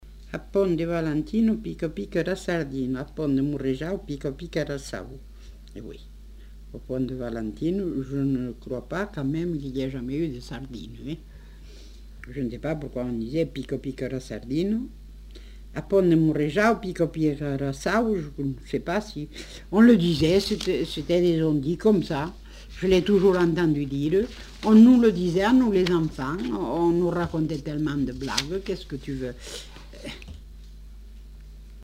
Formulette enfantine
Lieu : Montauban-de-Luchon
Genre : forme brève
Effectif : 1
Type de voix : voix de femme
Production du son : récité